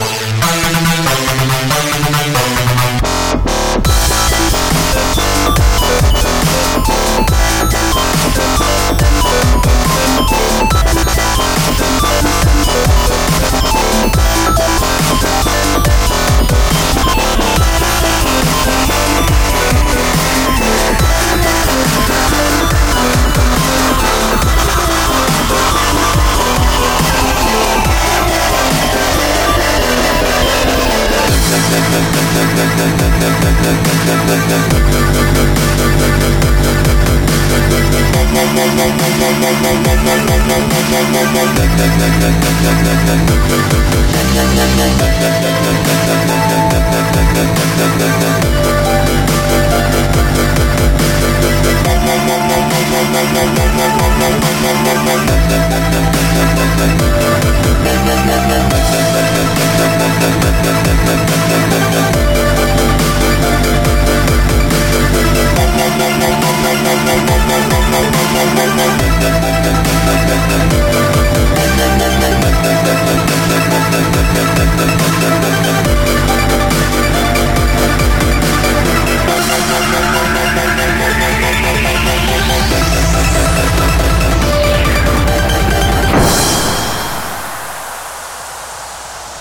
• Качество: 138, Stereo
громкие
жесткие
Electronic
Drumstep
Стиль: dubstep